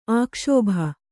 ♪ ākṣōbha